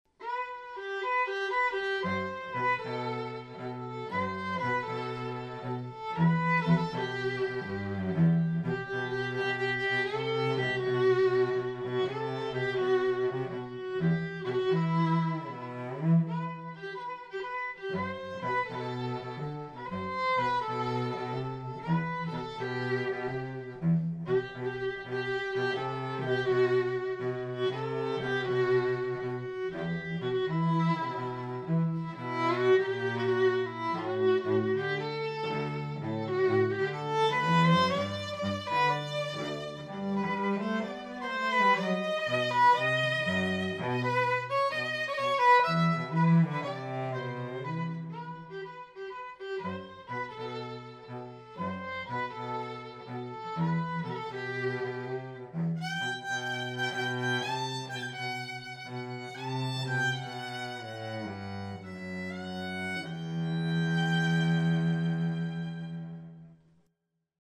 Pop, Rock, RnB